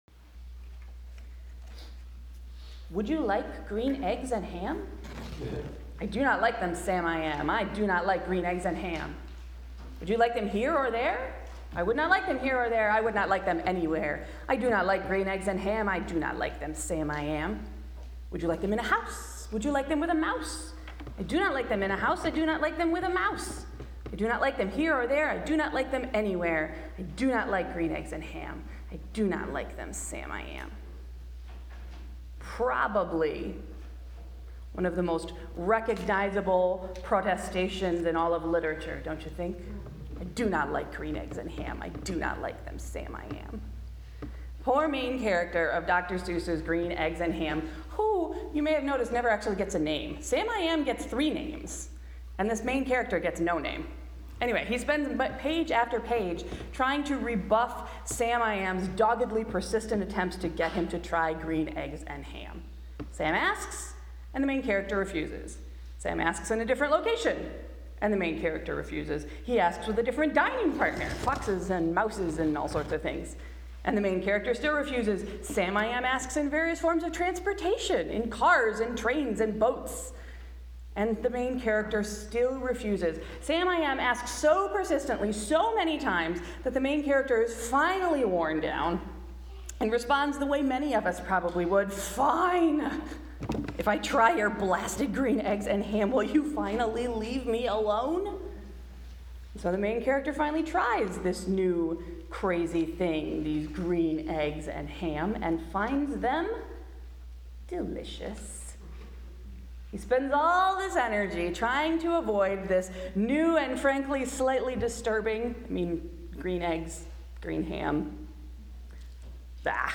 Sunday’s sermon: Tearing the Roof Off